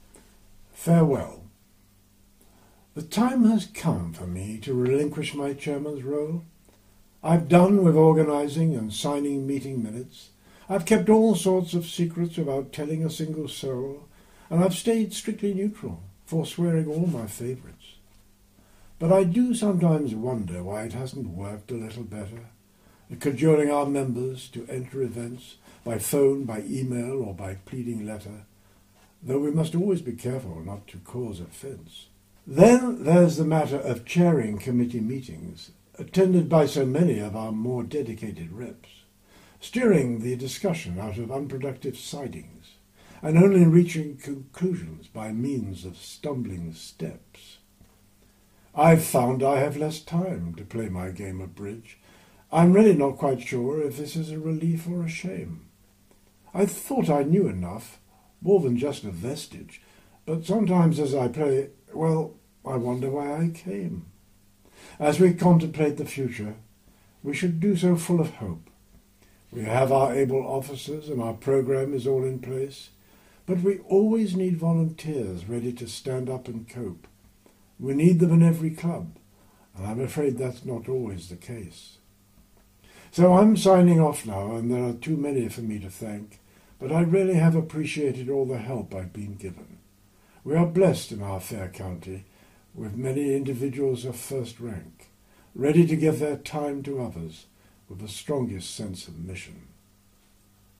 Delivered at the HBA AGM at Ross.